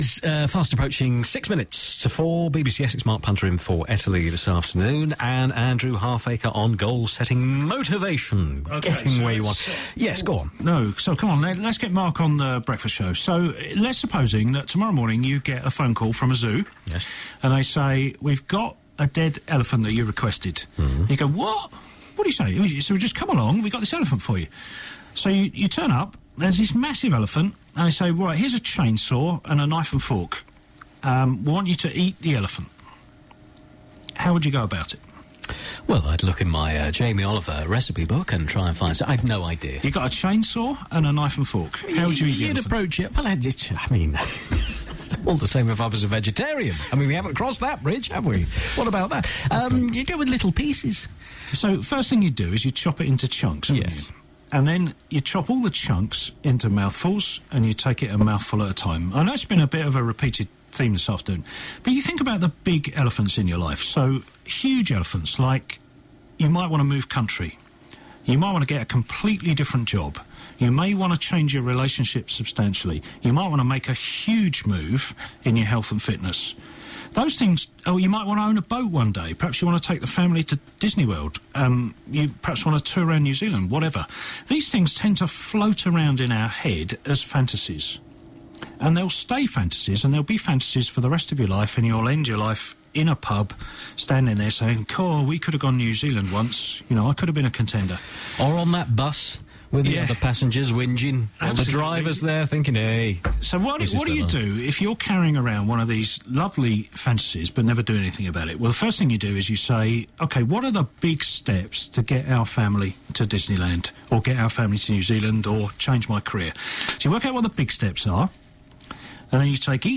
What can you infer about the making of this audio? All the best bits from my Sound Advice slot on BBC Essex 24 Jan 2012.